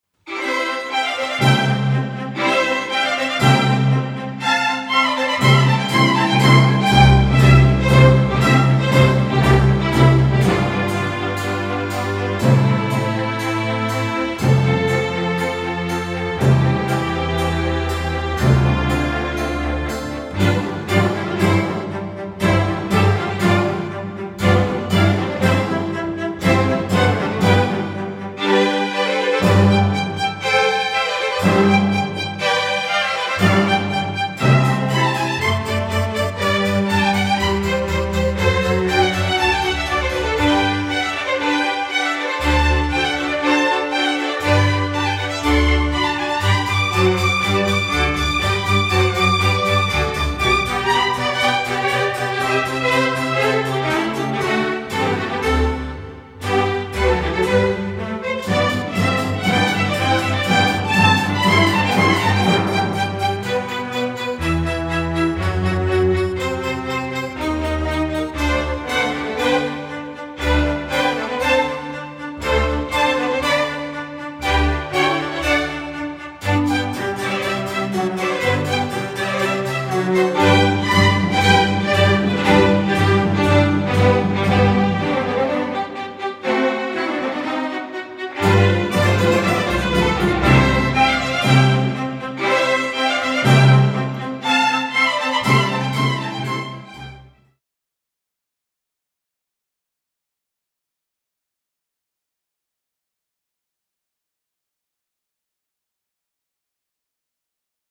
弦樂團